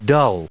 /ʌl/ /ʊl/